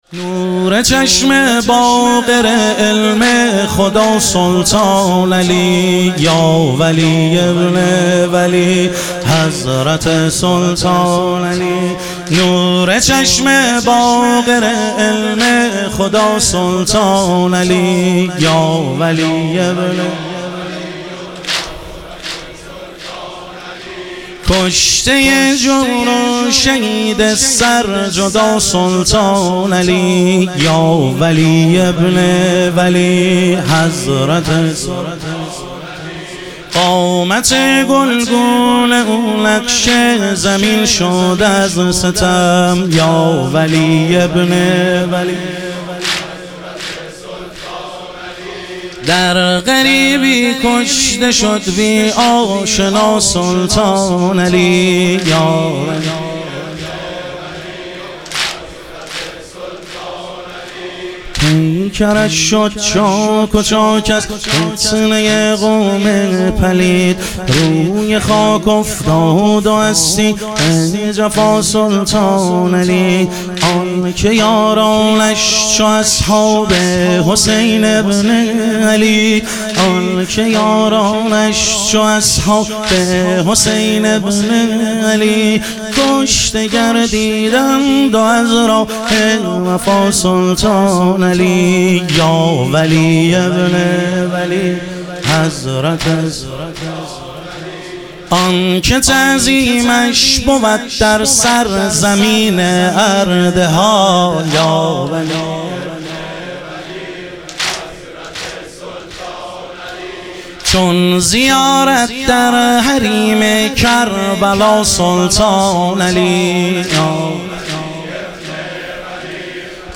شهادت حضرت سلطانعلی علیه السلام - واحد